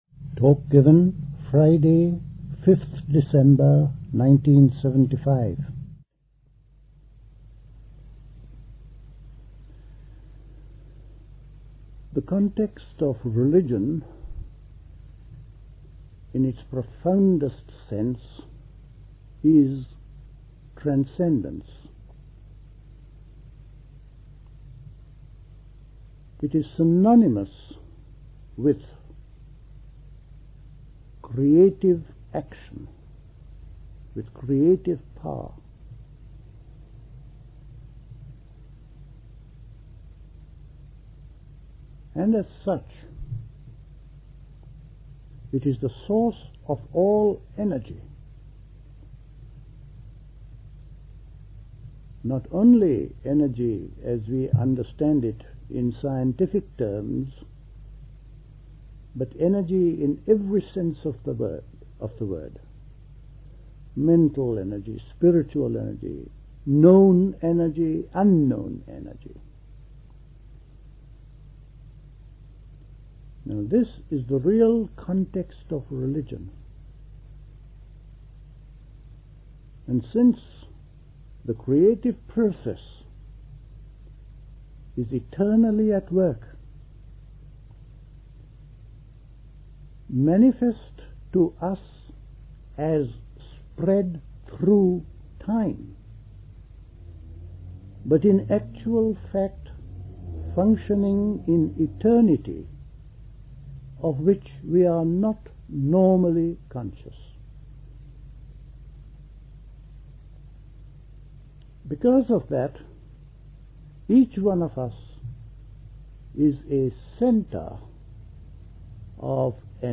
at Dilkusha, Forest Hill, London on 5th December 1975